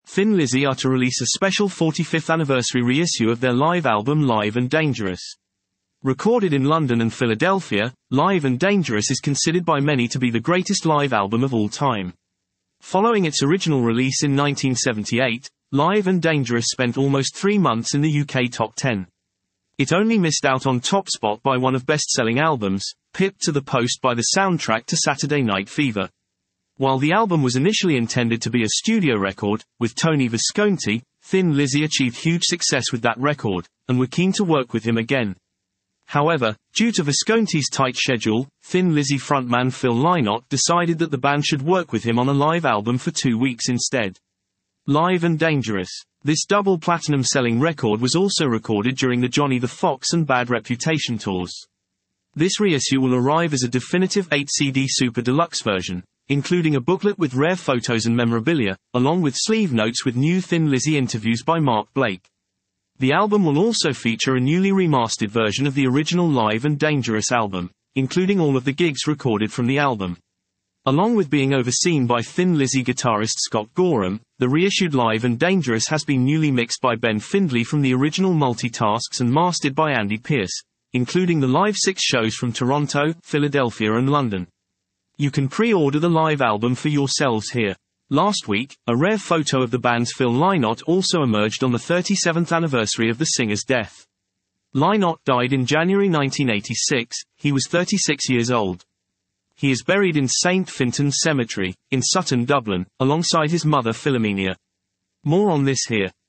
Recorded in London and Philadelphia